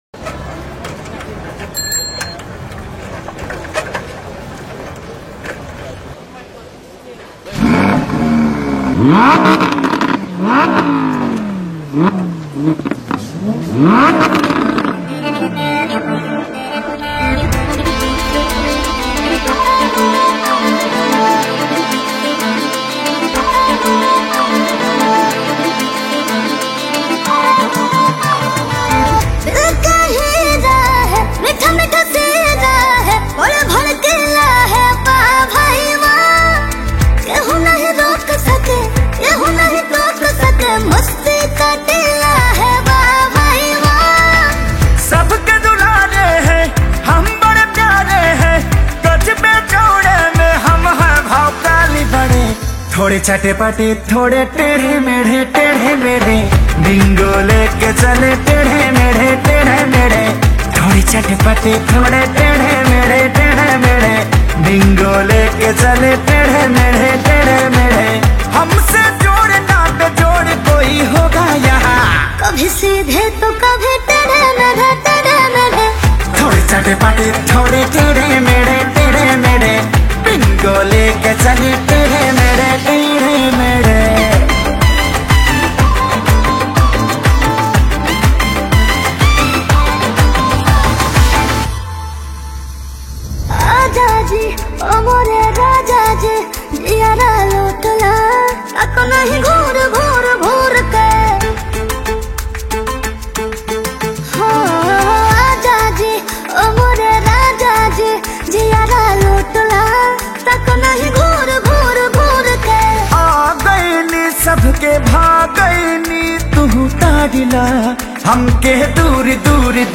Releted Files Of New Bhojpuri Song 2025 Mp3 Download